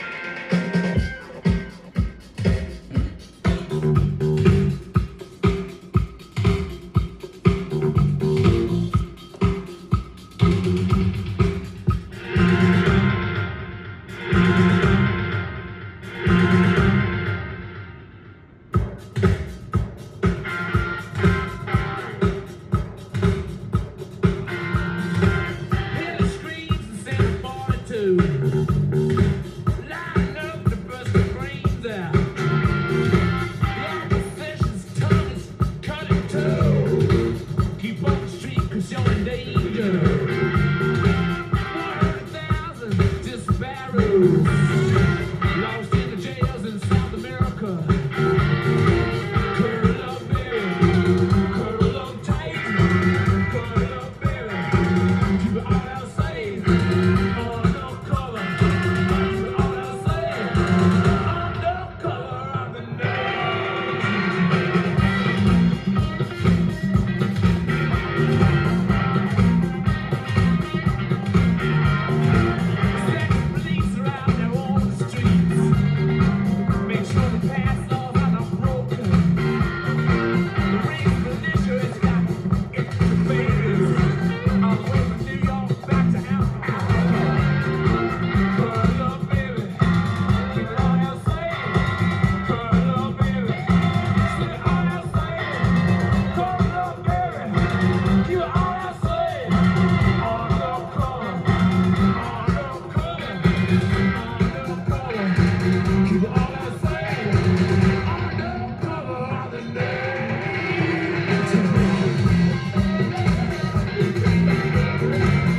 ジャンル：ROCK & POPS
店頭で録音した音源の為、多少の外部音や音質の悪さはございますが、サンプルとしてご視聴ください。
音が稀にチリ・プツ出る程度